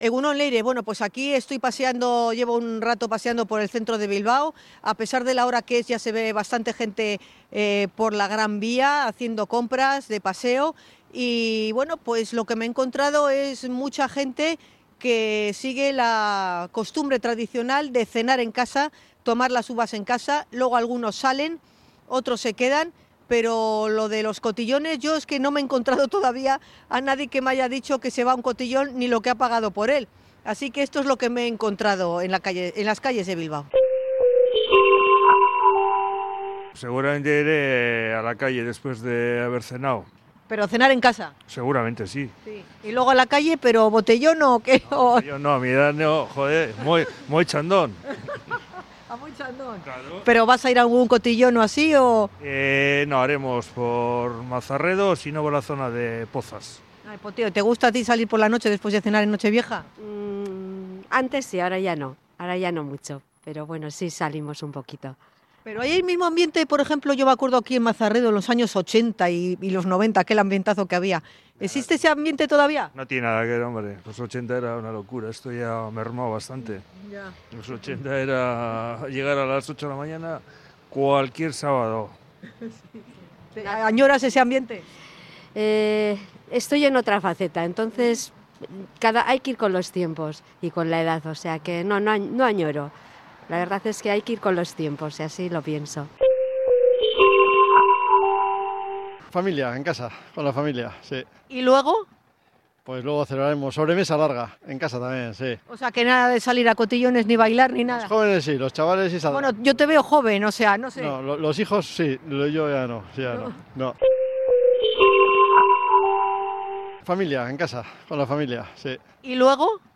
Encuesta callejera sobre Nochevieja
Salimos a la calle para saber si los vizcaínos somos más de Cotillón o de juego de mesa tras las uvas